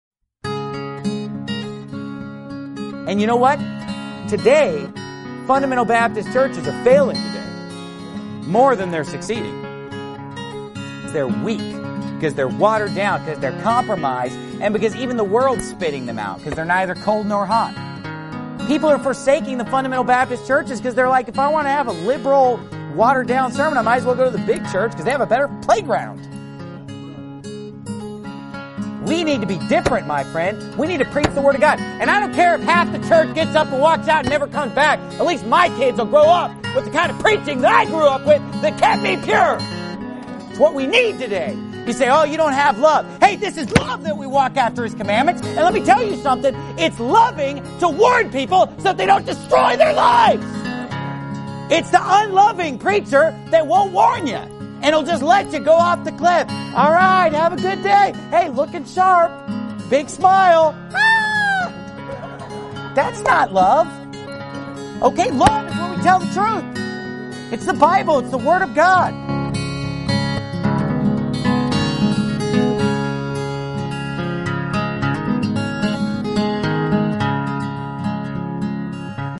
Hard_Preaching_Is_Love.mp3